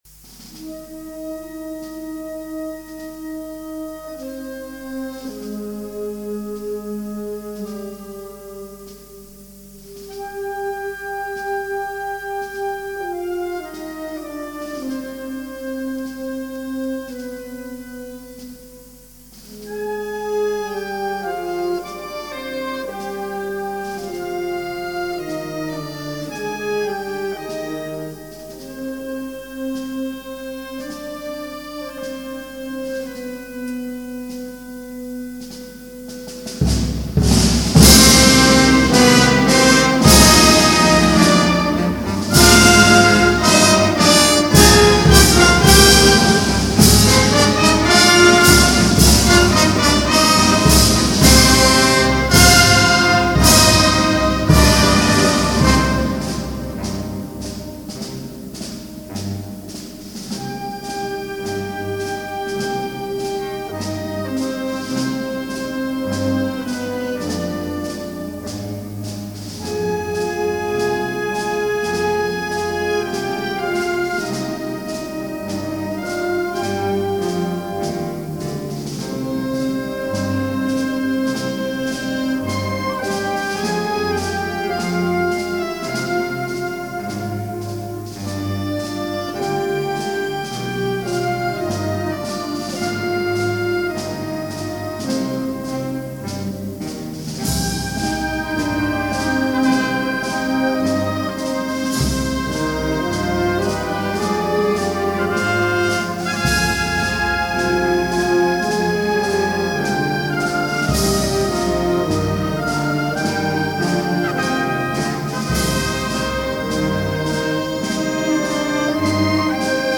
Marcha de Procesión Cristo del Rescate,